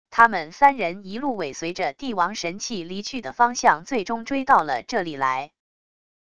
他们三人一路尾随着帝王神器离去的方向最终追到了这里來wav音频生成系统WAV Audio Player